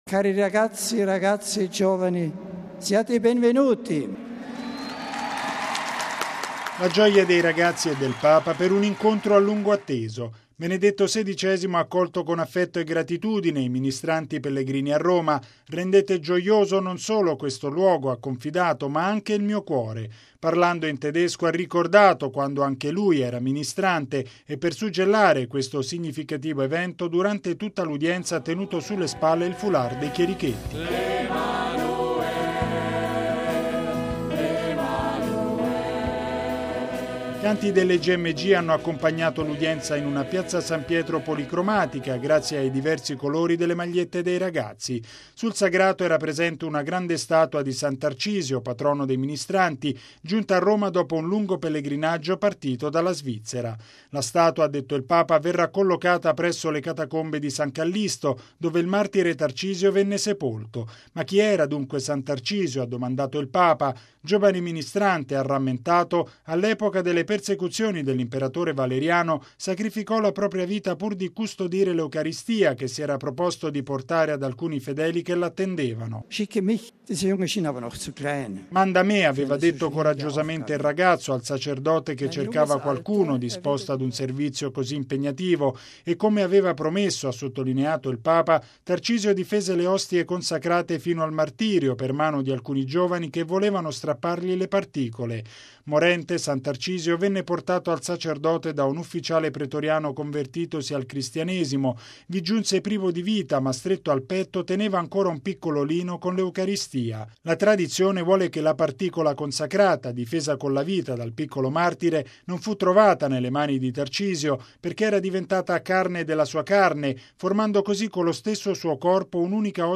◊   Il clima festoso delle Gmg ha caratterizzato l’udienza generale di Benedetto XVI stamani in Piazza San Pietro gremita da oltre 80 mila fedeli: protagonisti migliaia di giovani ministranti di tutta Europa, convenuti a Roma in questi giorni per il loro decimo pellegrinaggio.
“Cari ragazzi e giovani, siate i benvenuti!” (Applausi)
Canti delle Gmg hanno accompagnato l’udienza, in una Piazza San Pietro policromatica grazie ai diversi colori delle magliette dei ragazzi.